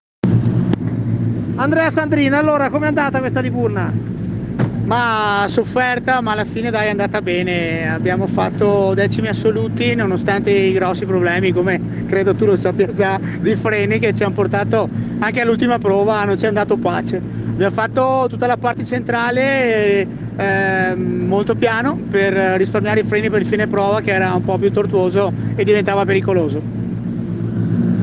Interviste Rally Liburna Terra 2021
Sabato - Interviste finali